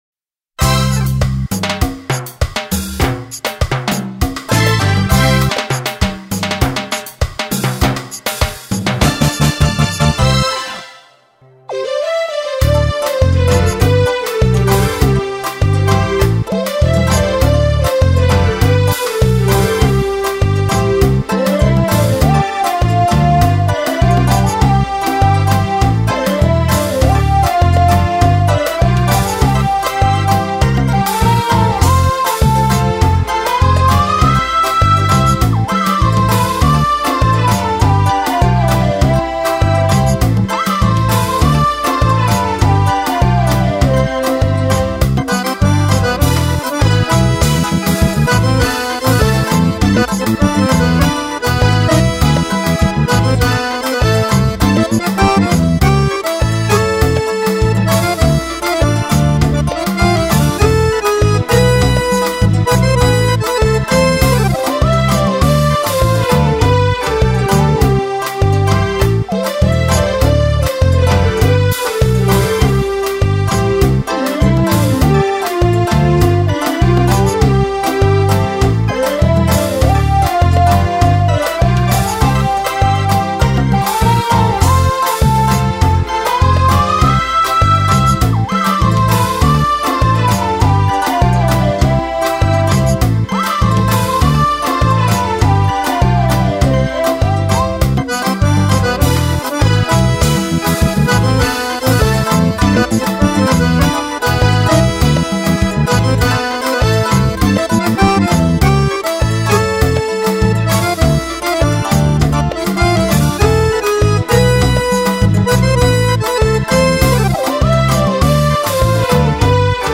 2976   03:35:00   Faixa: 3    Clássica